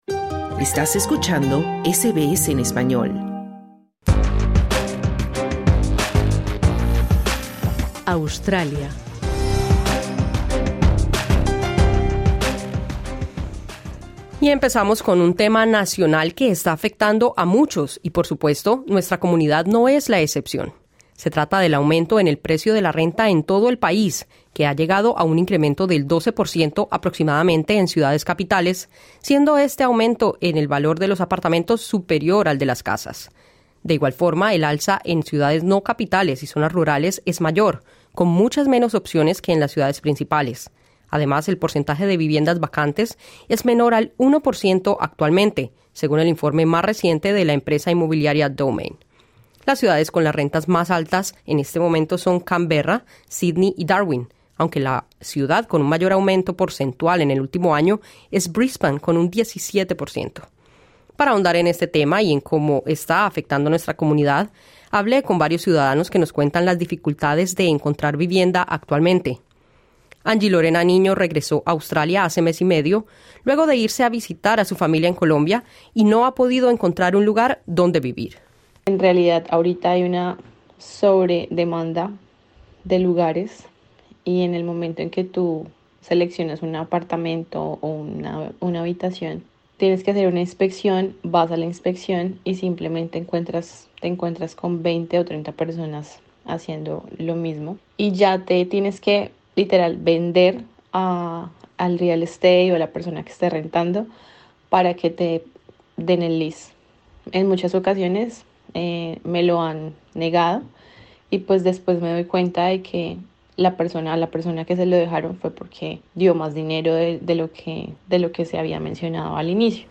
El mercado australiano de alquileres de viviendas está entre los más caros del mundo y se prevé que los precios seguirán aumentando por la inflación y gran demanda. SBS Spanish conversó con un grupo de hispanohablantes que están luchando para llegar a fin de mes.